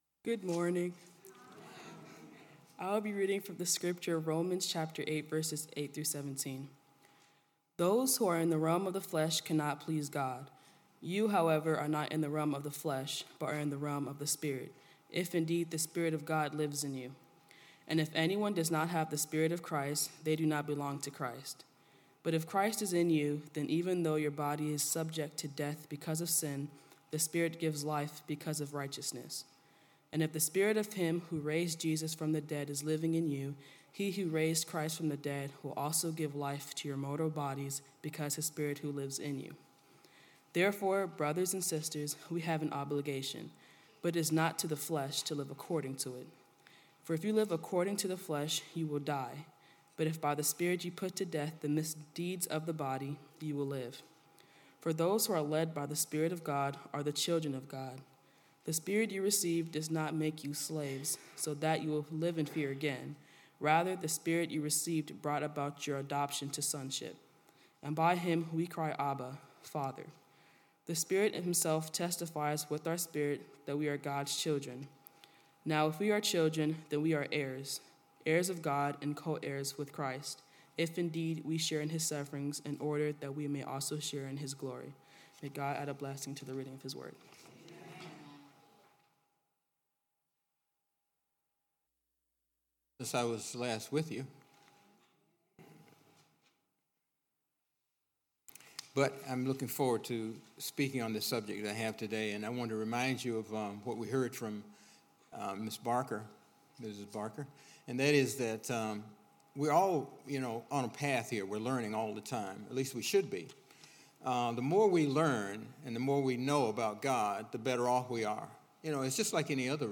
Worship Service 4/8/18